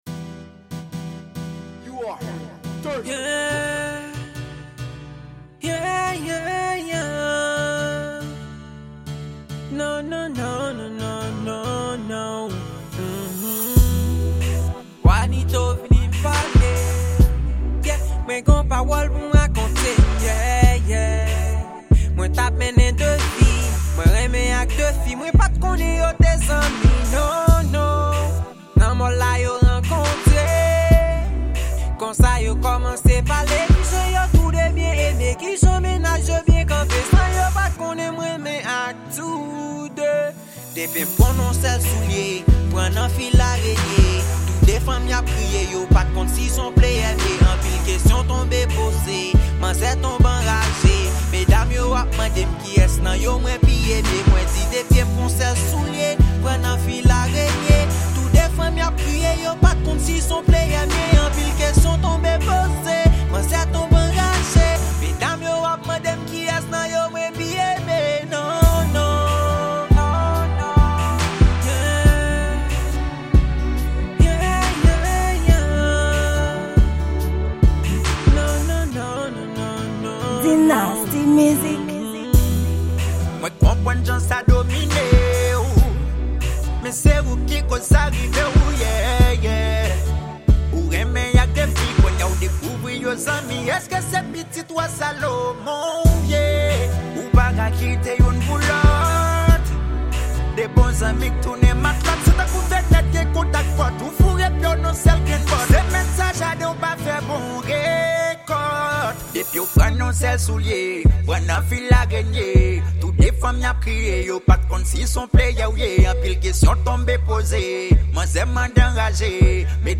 Genre: RnB.